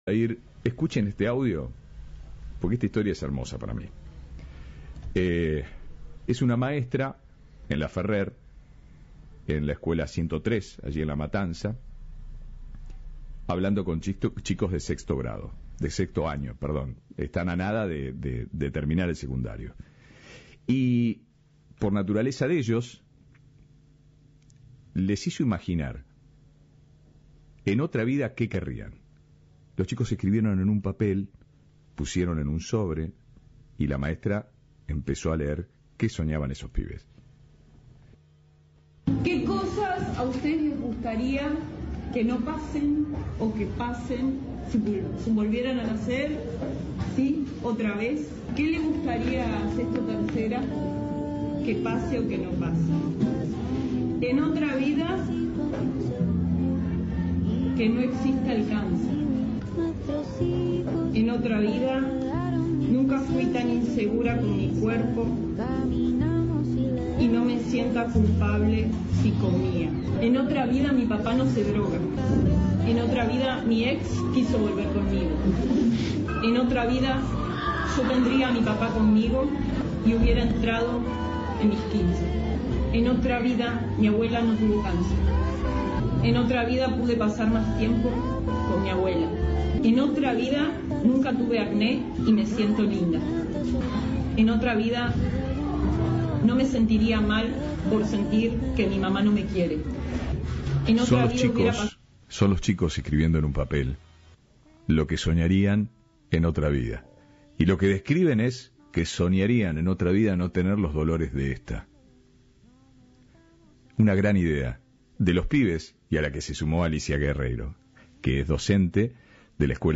Entrevista de Rodolfo Barili.